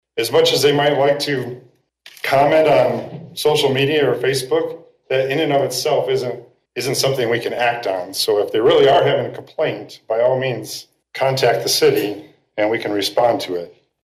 COLDWATER, MI (WTVB) – Coldwater city officials tried to address recent complaints about discolored and rusty looking water during this past Monday’s City Council meeting.
City Manager Keith Baker says if you have a problem, you can get a response if you call City Hall instead of posting comments and pictures on social media.